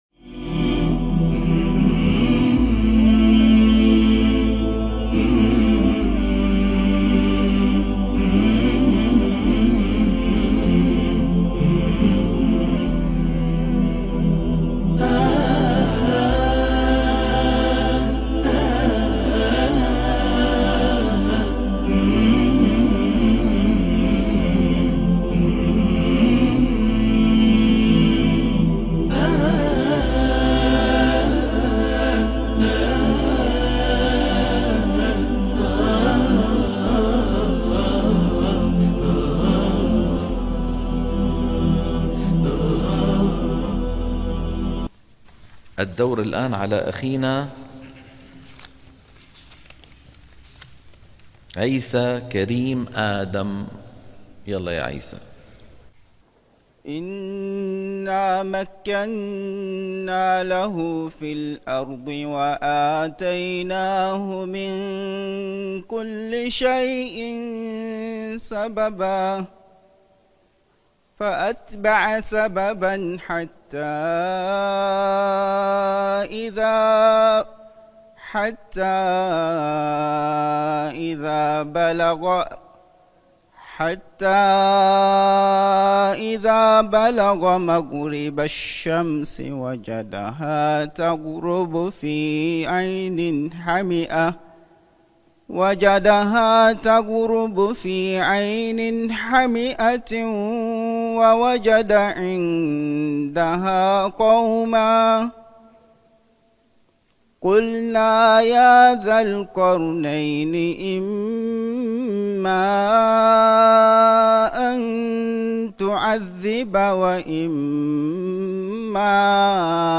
برنامج تصحيح التلاوة الحلقة - 90 - تصحيح التلاوة من الصفحة 303 إلى 305 - الشيخ أيمن سويد